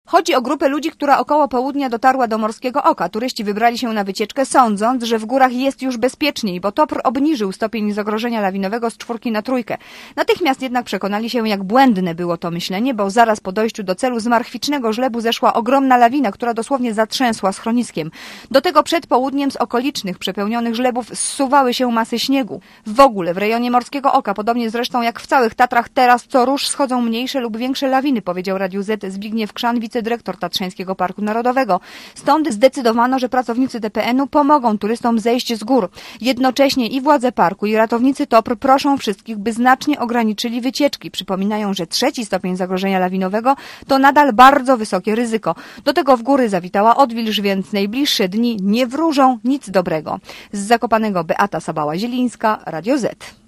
relacji